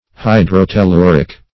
Search Result for " hydrotelluric" : The Collaborative International Dictionary of English v.0.48: Hydrotelluric \Hy`dro*tel*lu"ric\, a. [Hydro-, 2 + telluric.]
hydrotelluric.mp3